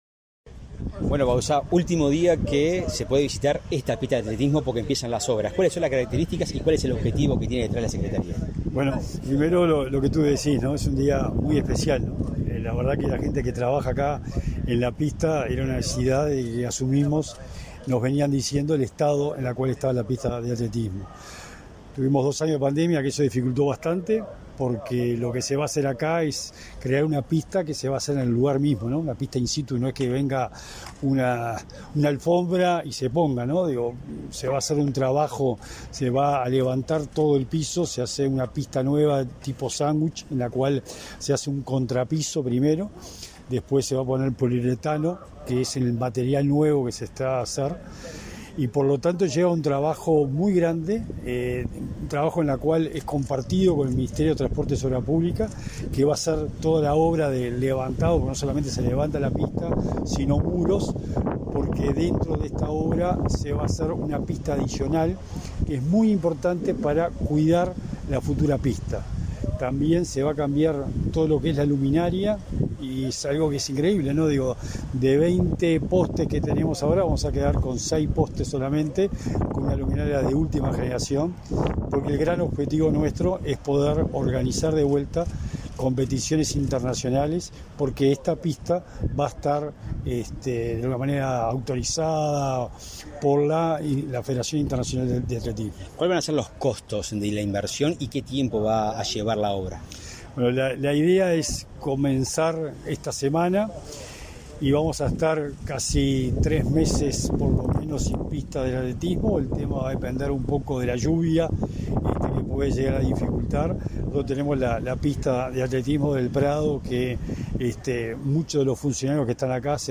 Entrevista al secretario nacional del Deporte, Sebastián Bauzá